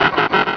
Cri d'Écrapince dans Pokémon Rubis et Saphir.